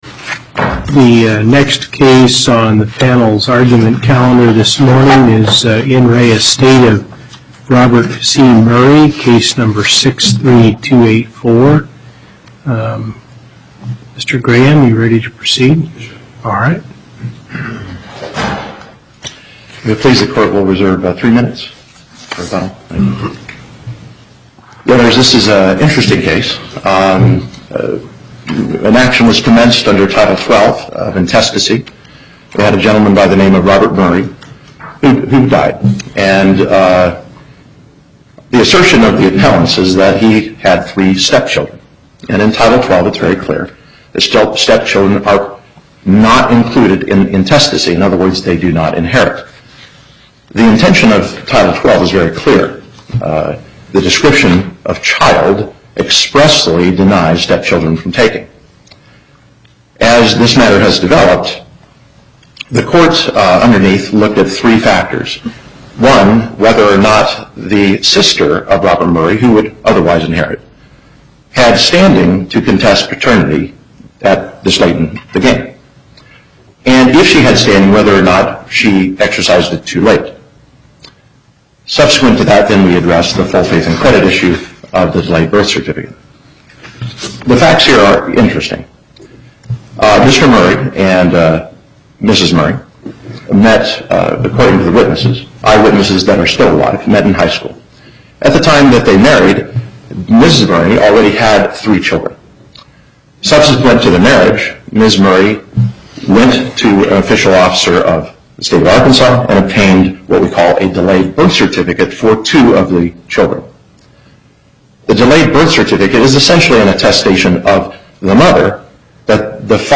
Before the Southern Nevada Panel, Justice Hardesty Presiding
as counsel for the Appellants
as counsel for the Respondent